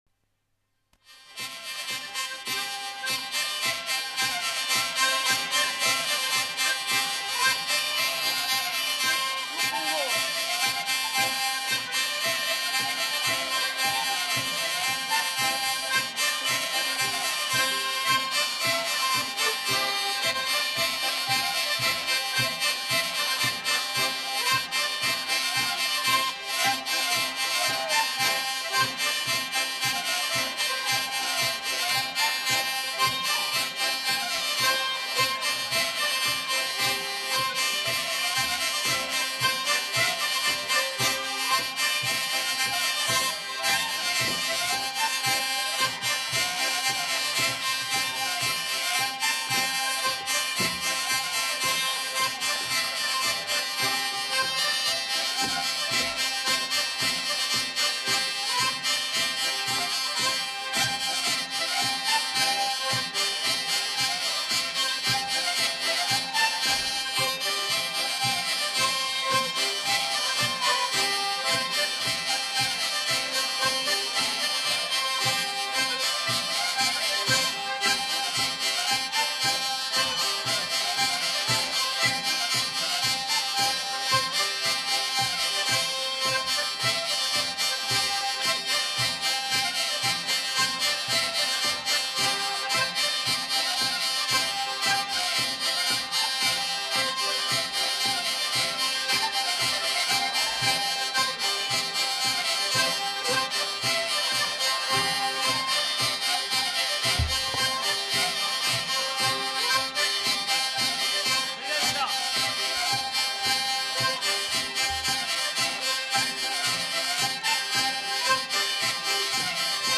Aire culturelle : Gabardan
Lieu : Houeillès
Genre : morceau instrumental
Instrument de musique : vielle à roue ; accordéon diatonique
Danse : congo